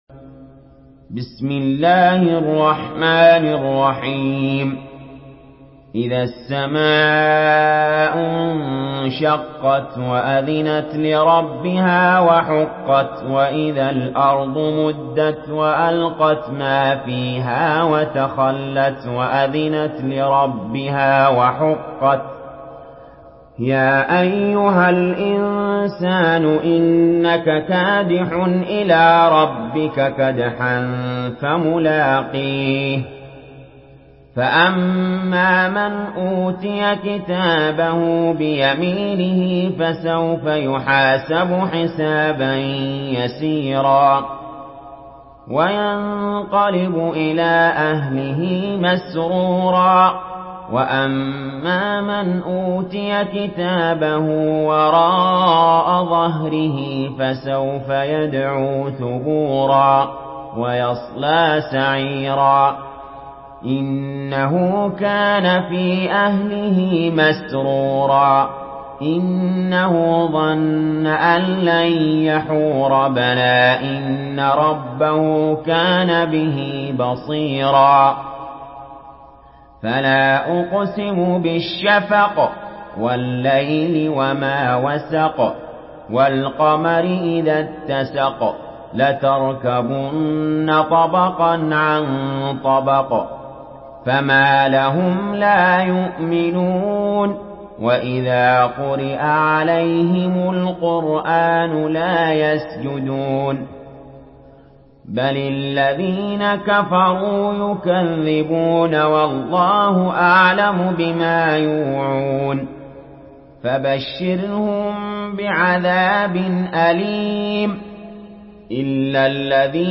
سورة الانشقاق MP3 بصوت علي جابر برواية حفص
مرتل